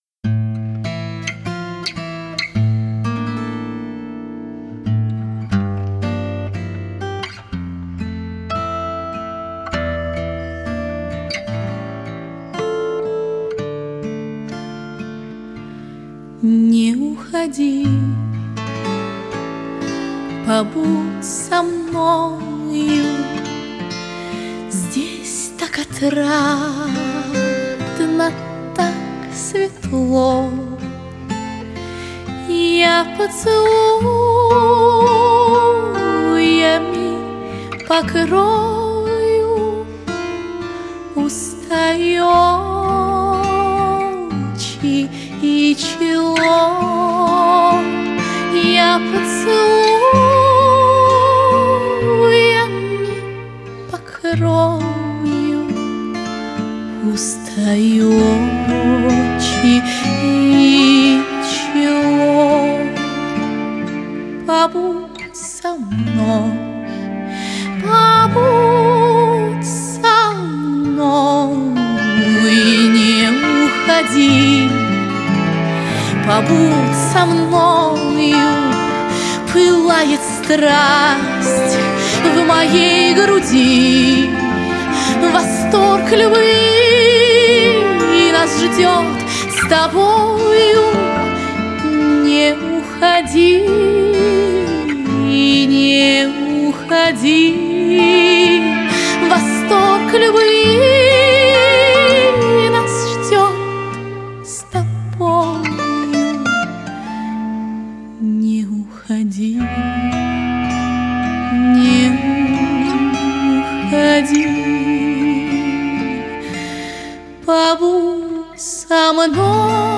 Her er hun i gang en gang i 2004, men vi slutter med en kendt romance, hvoraf Pelageja synger første og tredje strofe.